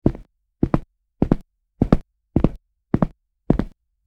Heavy Boots Walking
Heavy_boots_walking.mp3